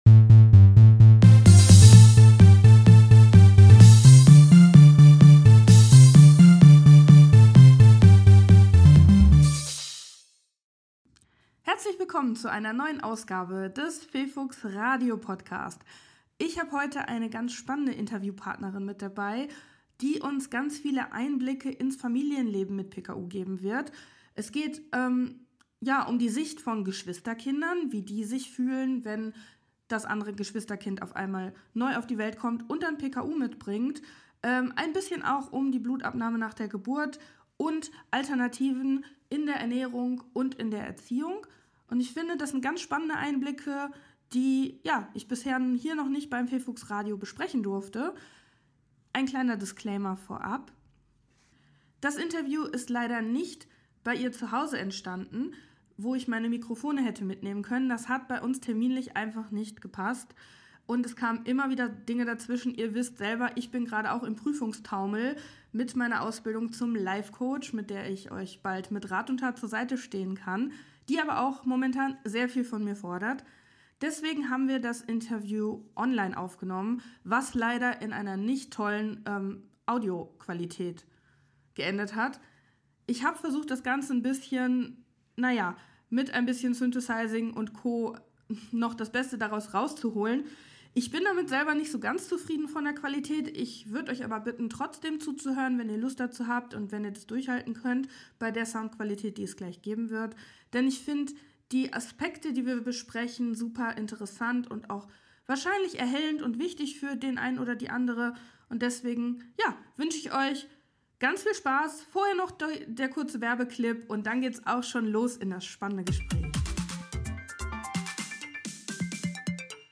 Wobei, so richtig zusammensetzen konnten wir uns leider nicht und haben dann kurzerhand die Folge online aufgenommen. Nun konnte ich aber so meine professionellen Mikrofone nicht nutzen, weswegen die Folge etwas an Soundqualität einbüßt, was sie aber - wie ich finde - inhaltlich wieder wett macht.
Die genutzte Musik ist selbst komponiert und ich nutze generell keine KI - alles Handarbeit hier.